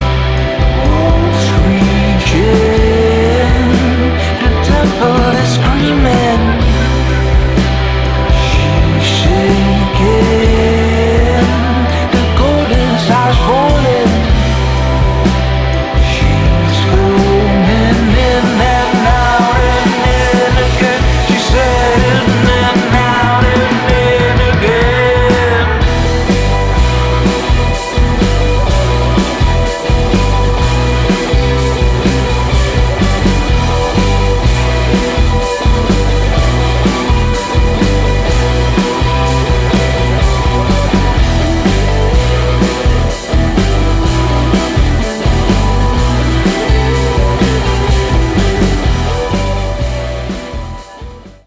alternative rock/pop
Bass
suara yang lebih raw and upbeat